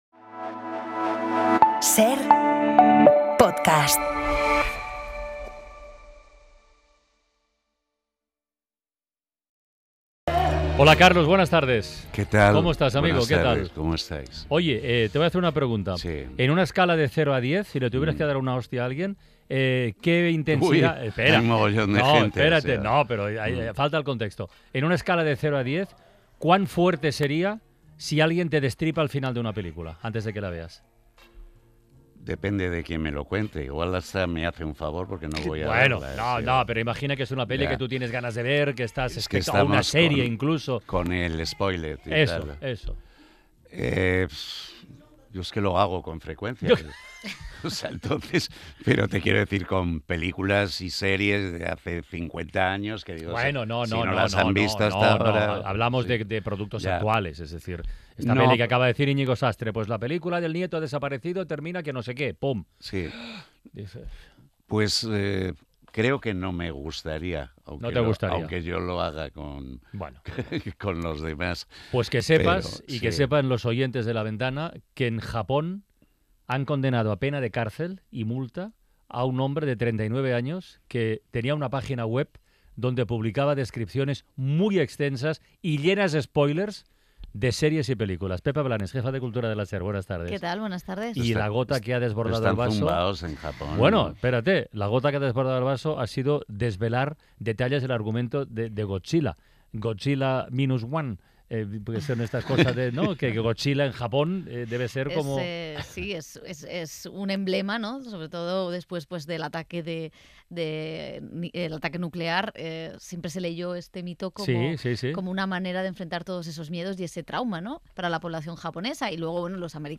Nuestro crítico de cine Carlos Boyero visita 'La Ventana' para hablarnos de los nuevos estrenos que llegan a la cartelera esta semana.